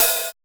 626 OHH.wav